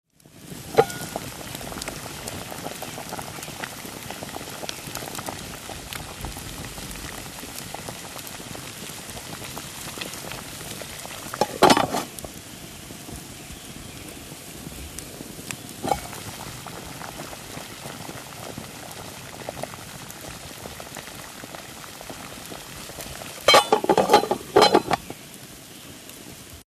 Boiling Water|Exterior | Sneak On The Lot
DINING - KITCHENS & EATING BOILING WATER: EXT: Campfire with pot of boiling water, lid on & off.